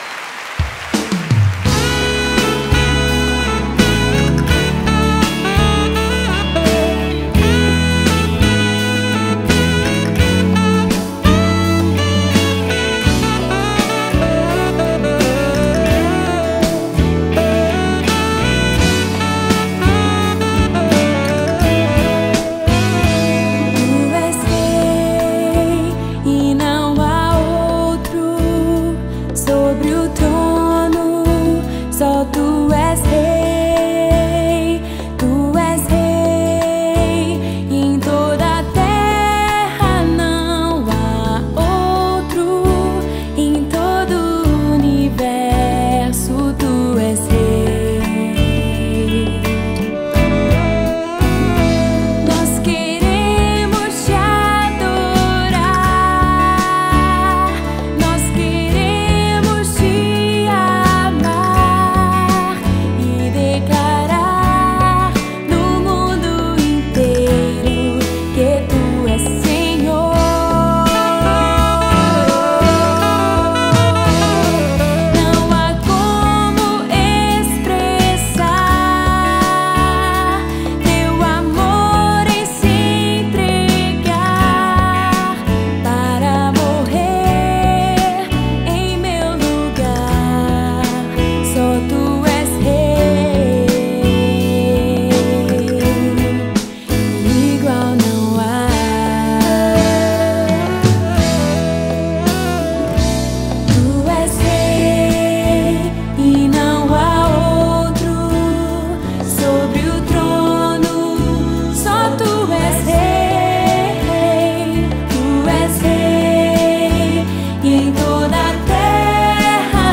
BPM 120